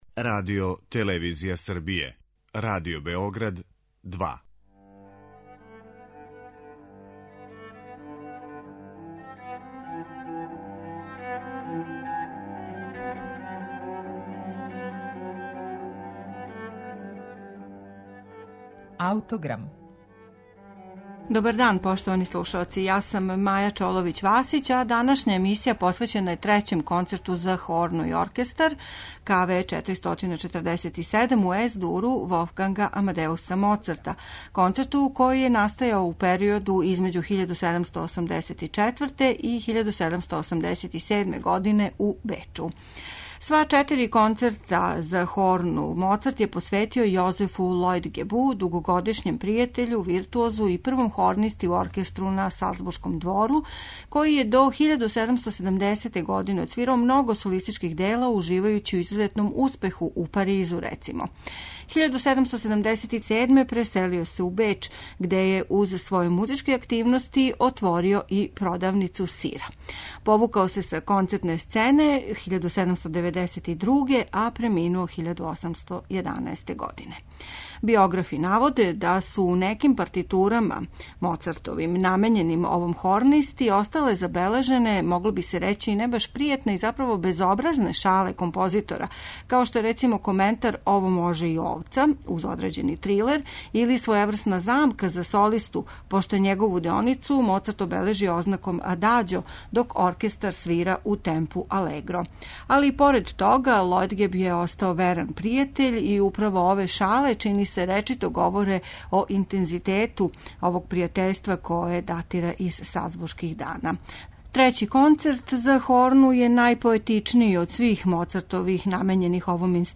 Концерт за хорну и оркестар, Волфганга Амадеуса Моцарта
У Аутограму слушамо Концерт за хорну и оркестар бр. 3 у Ес дуру Волфганга Амадеуса Моцарта из 1787. године, који је као и преостала три концерта за овај инструмент посвећен виртуозу из Салцбурга Јозефу Лојтгебу. Једно од омиљених дела хорниста изводи славни Херман Бауман.